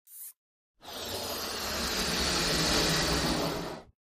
SFX of Magic Powerful Ballooned Ripcord